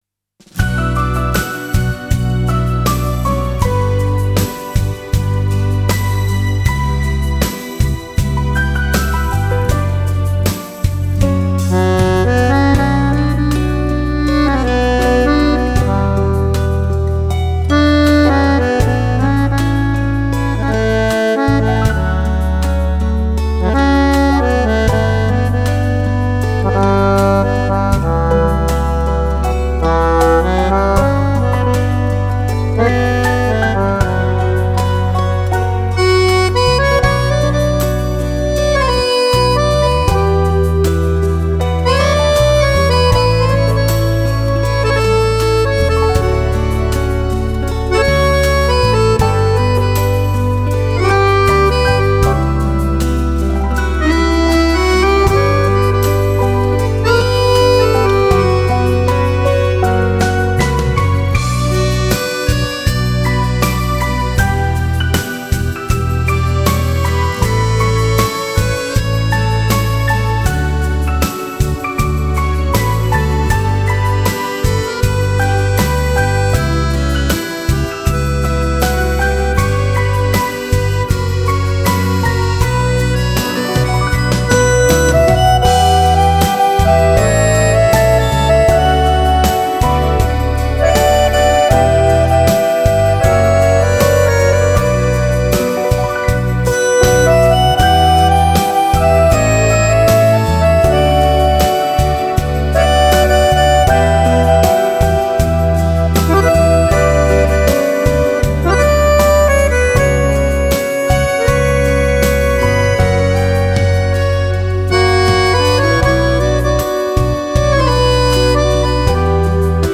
Инструментал (аккордеон)